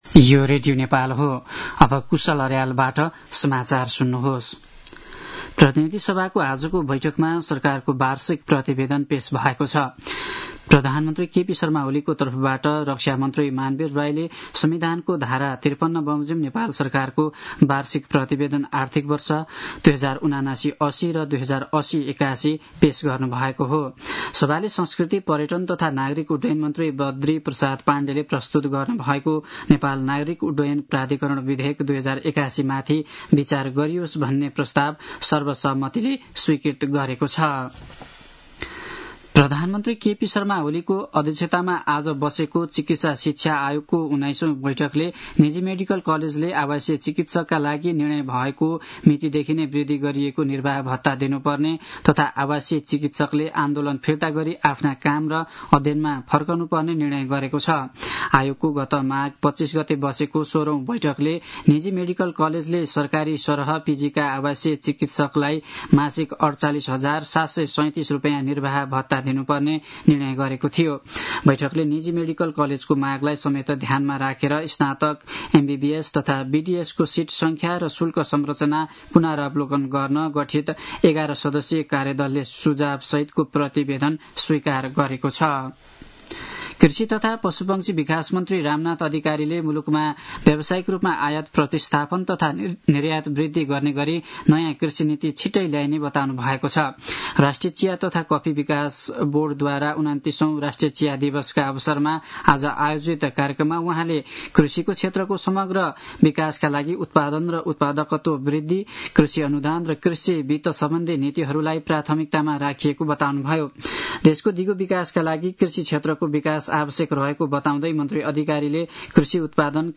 दिउँसो ४ बजेको नेपाली समाचार : १५ वैशाख , २०८२
4-pm-news-1-11.mp3